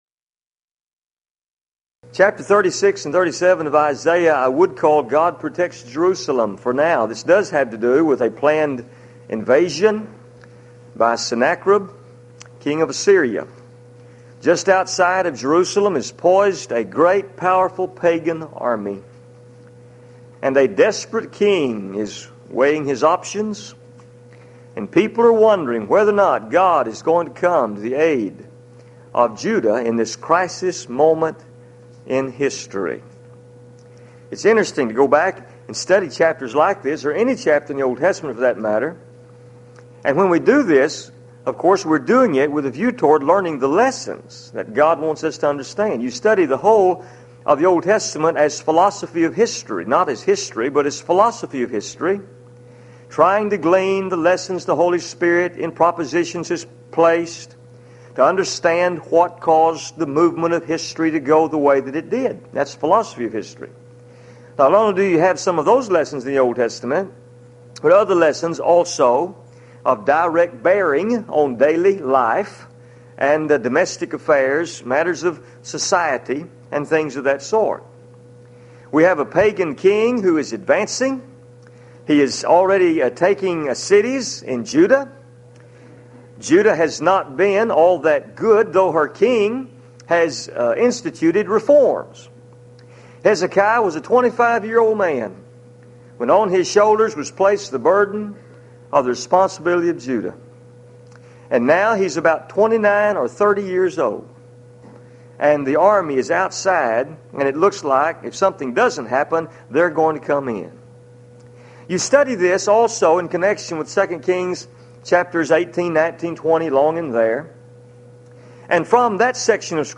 Series: Houston College of the Bible Lectures